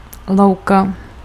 Ääntäminen
Synonyymit champ prairie Ääntäminen France: IPA: [pʁe] Haettu sana löytyi näillä lähdekielillä: ranska Käännös Ääninäyte Substantiivit 1. louka {f} 2. lučina 3. palouk Muut/tuntemattomat 4. pastvina {f} Suku: m .